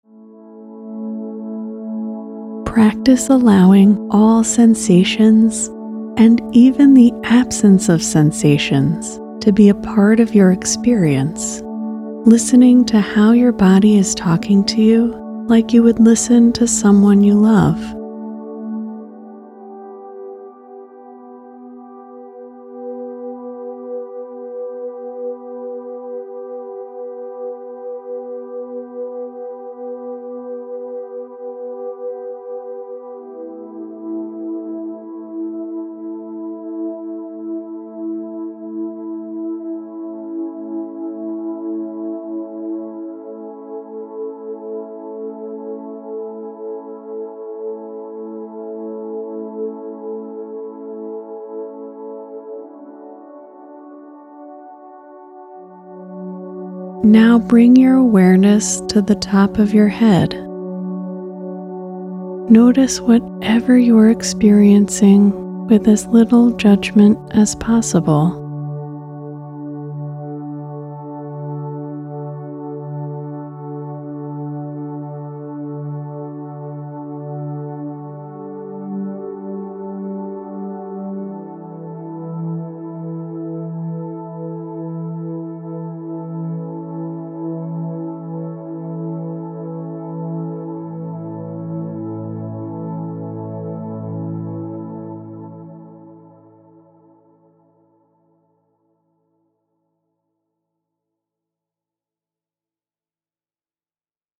This body scan meditation will deepen your connection to your body, as you’re guided in the practice of mindfully observing any sensations you notice from head to toe.
body-scan-meditation-preview.mp3